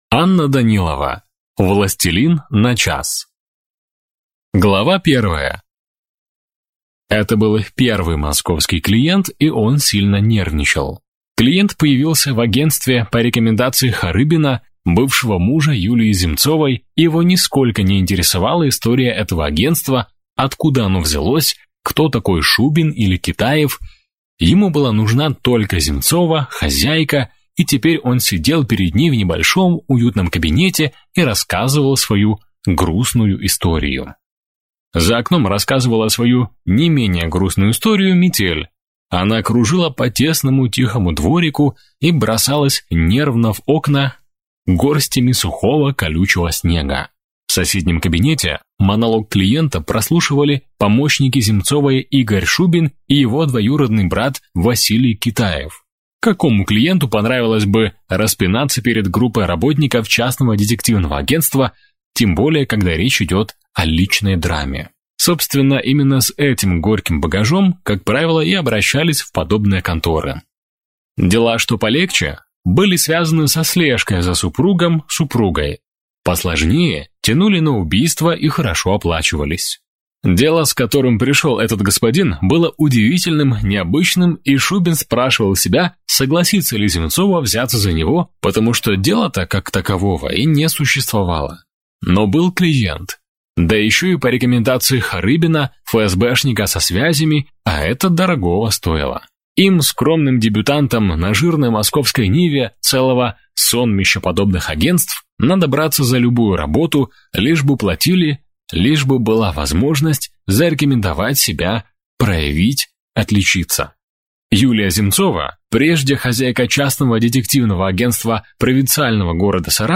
Аудиокнига Властелин на час | Библиотека аудиокниг